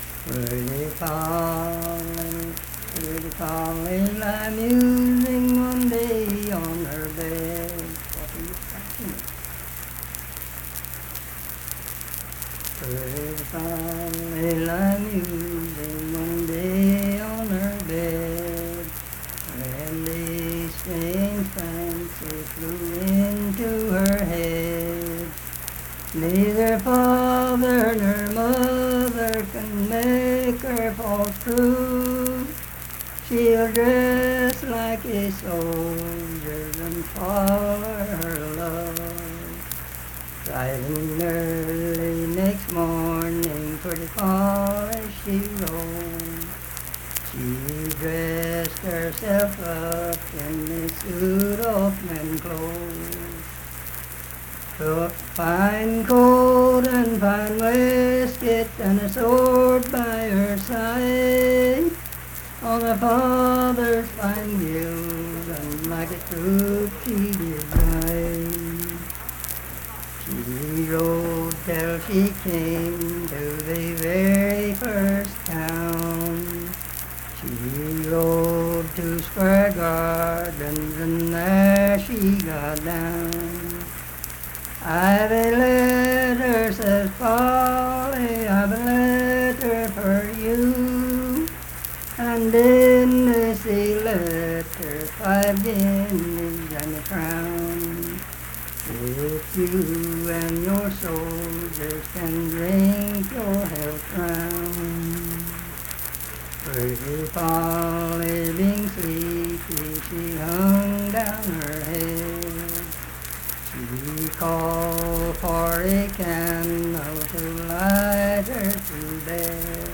Unaccompanied vocal music
Voice (sung)
Lincoln County (W. Va.), Harts (W. Va.)